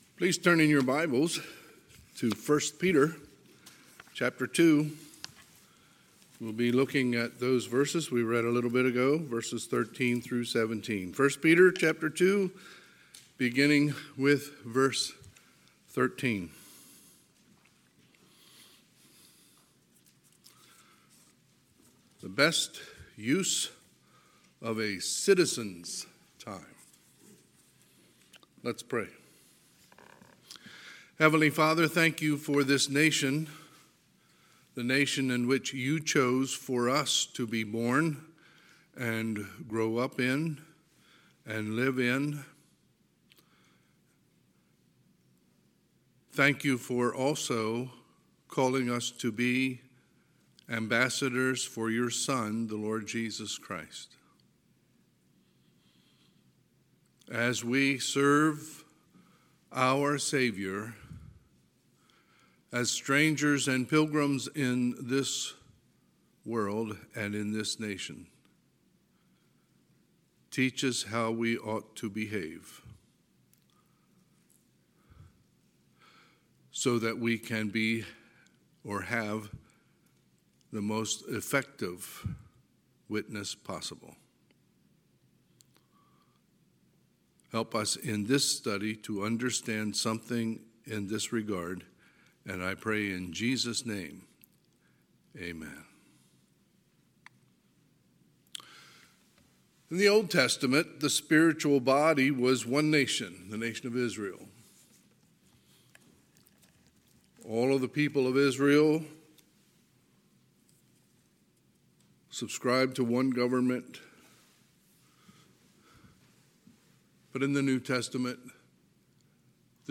Sunday, July 3, 2022 – Sunday AM